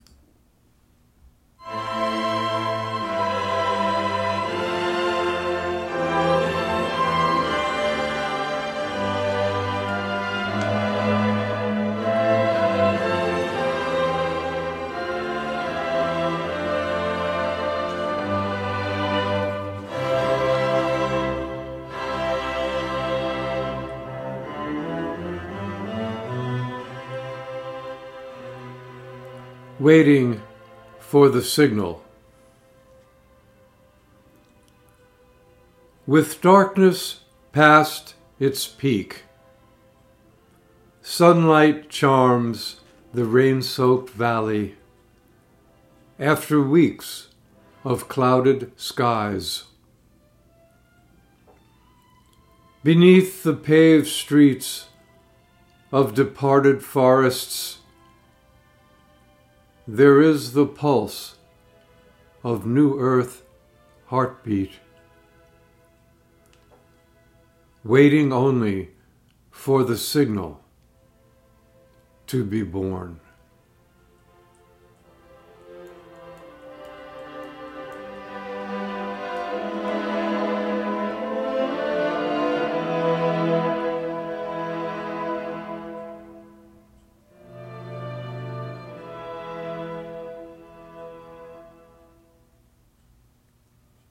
Reading of “Waiting for the Signal” with music by Tchaikovsky